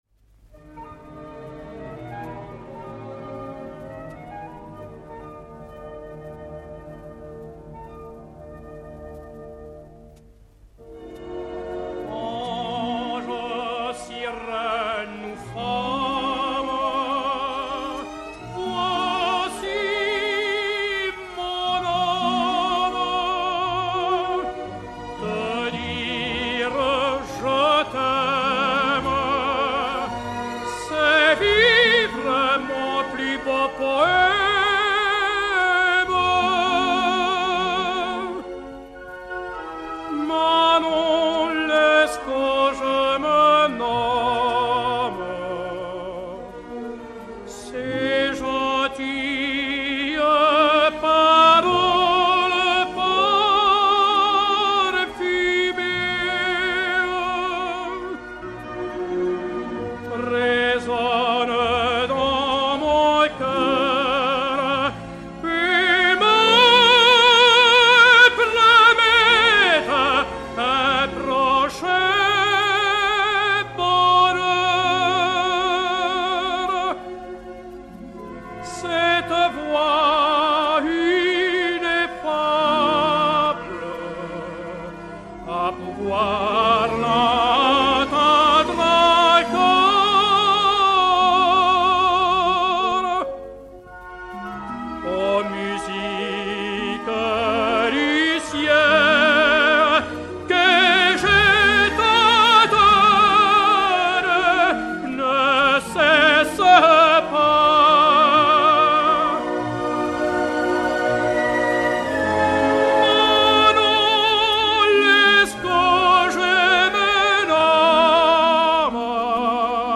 ténor français